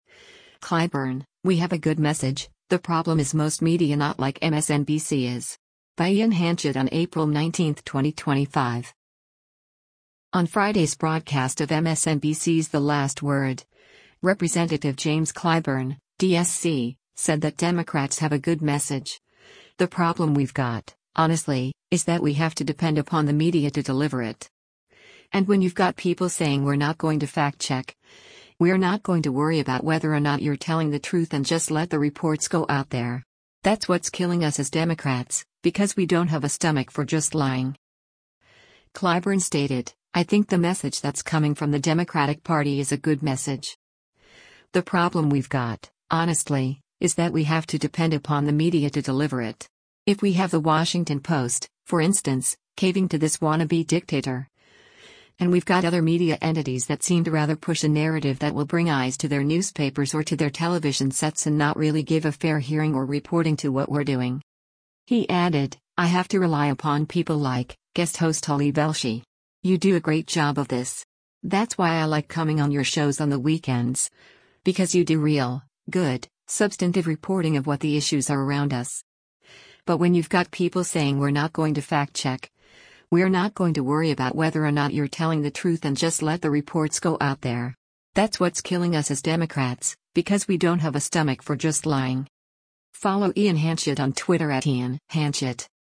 On Friday’s broadcast of MSNBC’s “The Last Word,” Rep. James Clyburn (D-SC) said that Democrats have a good message, “The problem we’ve got, honestly, is that we have to depend upon the media to deliver it.”